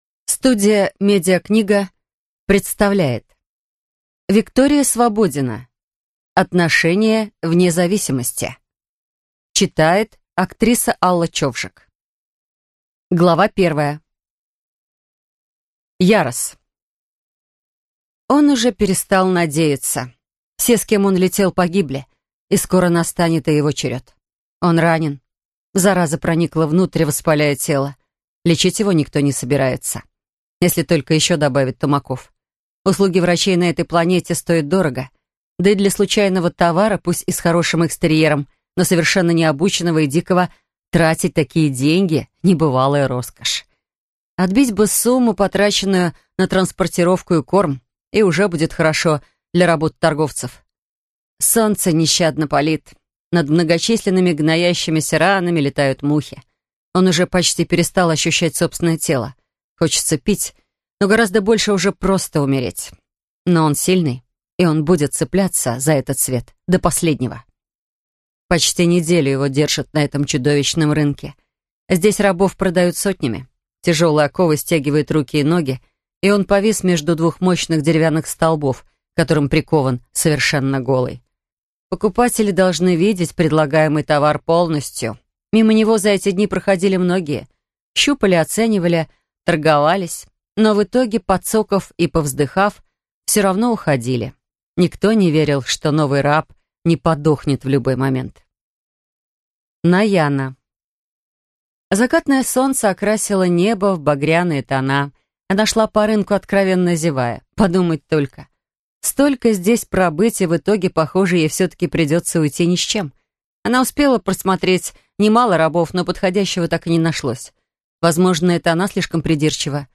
Аудиокнига Отношения вне зависимости | Библиотека аудиокниг